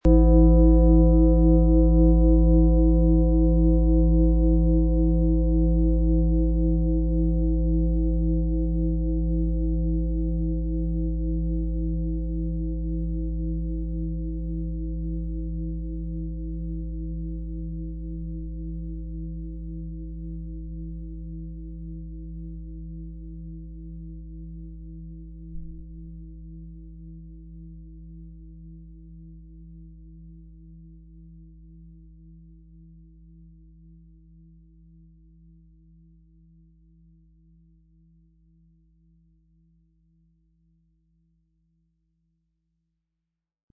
XXXL Fußreflexzonenschale
Spüre die magische Besonderheit des Planetenton Hopi-Herzton - berührende und intensive Vibrationen im XXXL Format
Ihr kraftvoller, harmonischer Klang hüllt dich ein, berührt dein Herz und schafft einen Raum der Geborgenheit.
Ihre kraftvollen, sanften und lang anhaltenden Vibrationen durchströmen dich und schenken ein Gefühl von Leichtigkeit, Harmonie und tiefer Entspannung.
Um den Original-Klang genau dieser Schale zu hören, lassen Sie bitte den hinterlegten Sound abspielen.
Sanftes Anspielen mit dem gratis Klöppel zaubert aus Ihrer Schale berührende Klänge.